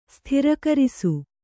♪ sthirakarisu